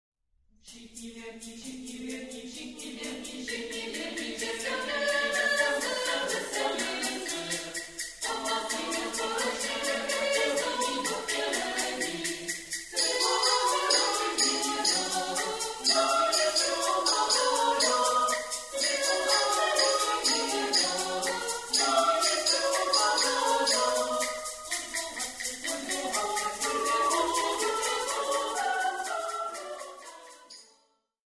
Komorní pěvecké sdružení Ambrosius
Demo nahrávka, prosinec 2000